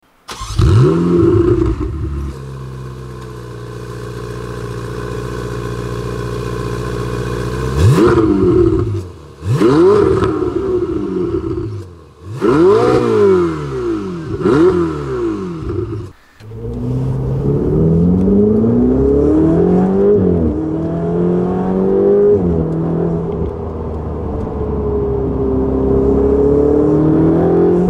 ENGINE SIZE 3.9L V8